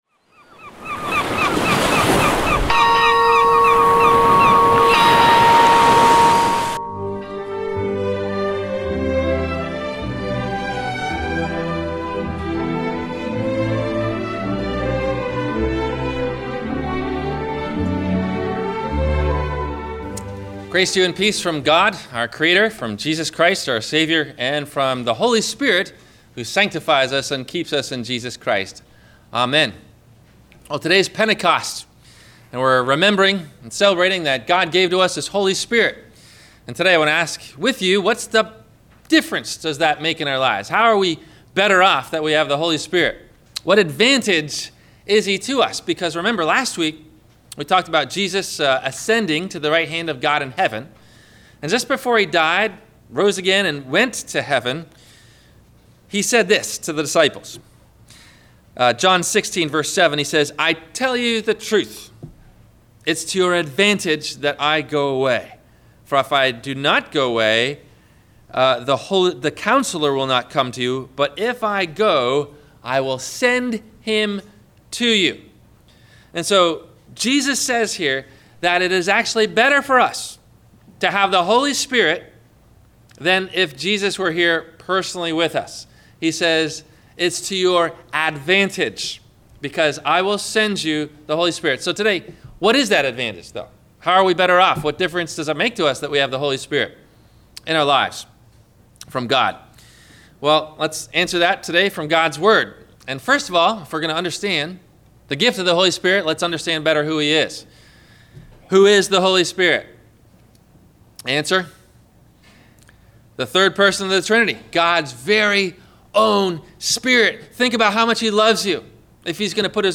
Do You Have The Holy Spirit of God in You? – WMIE Radio Sermon – June 29 2015